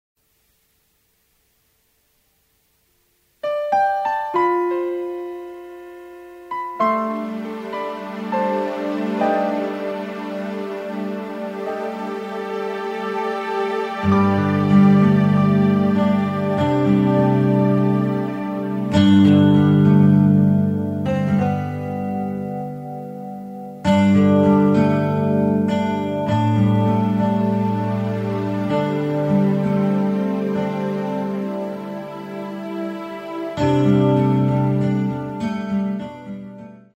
Muestras de las pistas